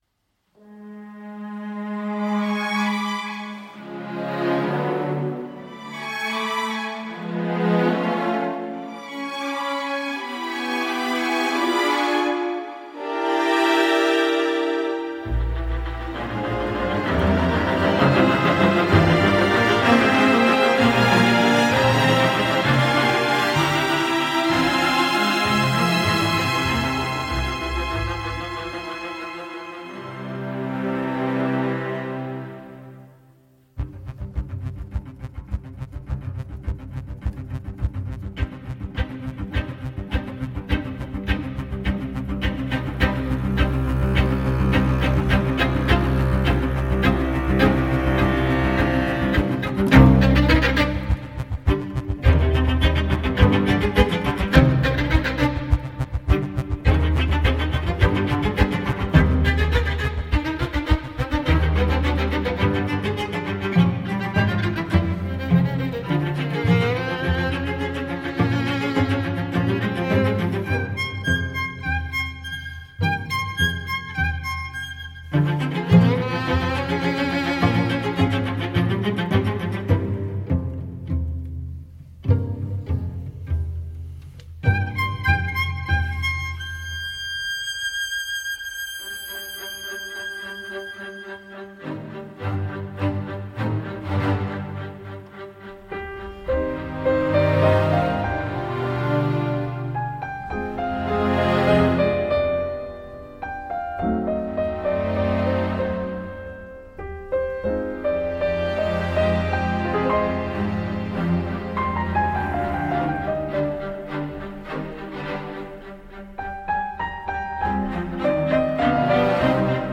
Le final, fort en émotion, pince le cœur.